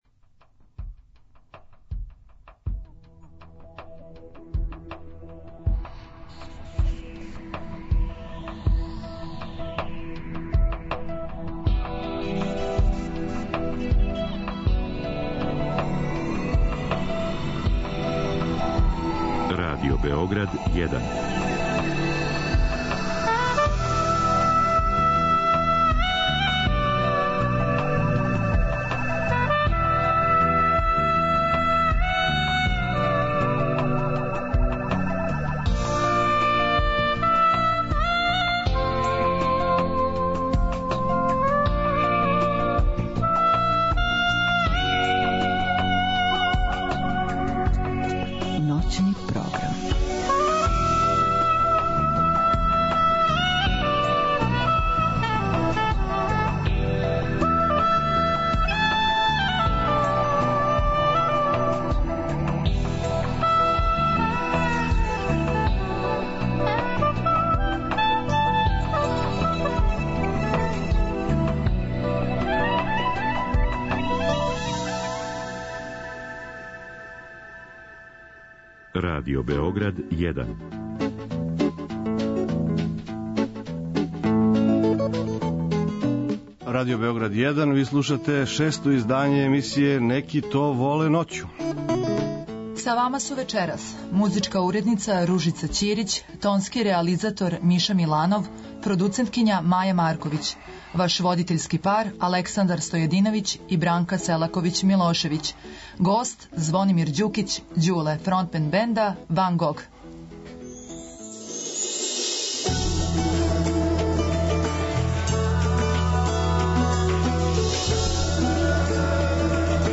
преузми : 56.22 MB Ноћни програм Autor: Група аутора Сваке ноћи, од поноћи до четири ујутру, са слушаоцима ће бити водитељи и гости у студију, а из ноћи у ноћ разликоваће се и концепт програма, тако да ће слушаоци моћи да изаберу ноћ која највише одговара њиховом укусу, било да желе да слушају оперу или их интересује технологија.